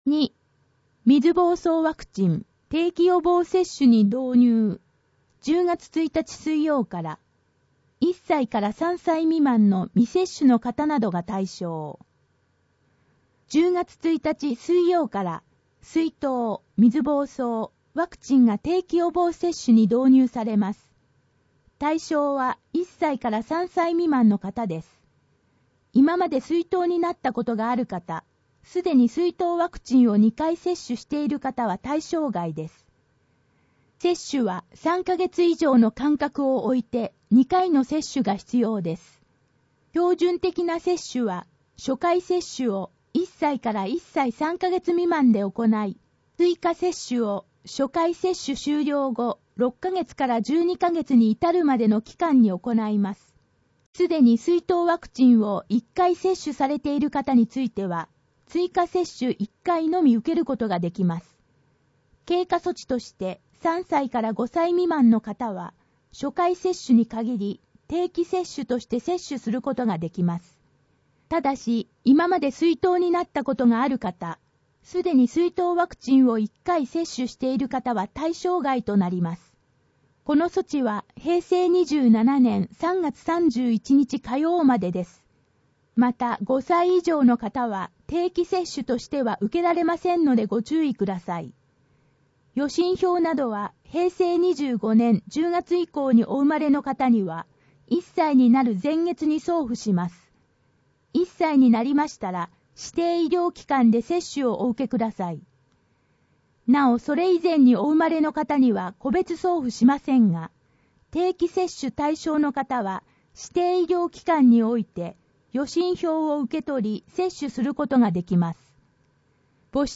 声の広報 平成26年8月21日号（1-8面）